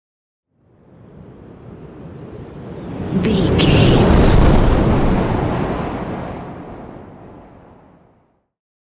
Whoosh fire large
Sound Effects
dark
intense
whoosh